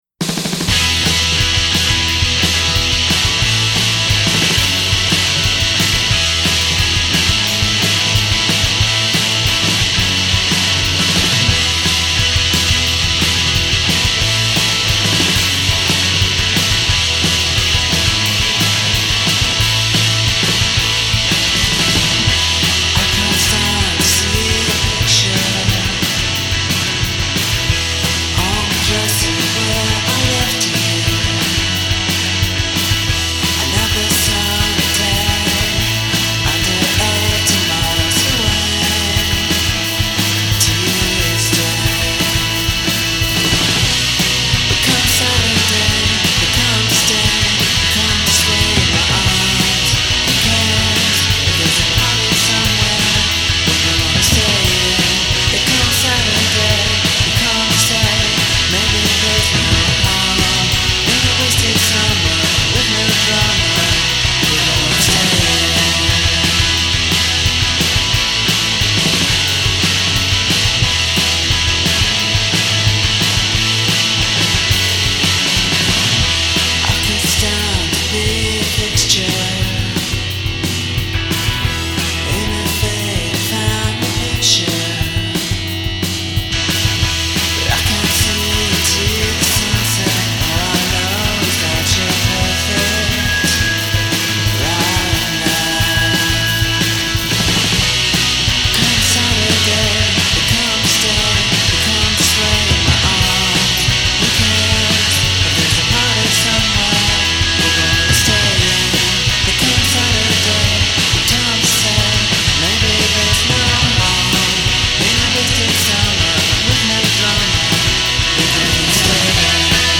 indiepop
Mi ricordano un pò gli smiths :)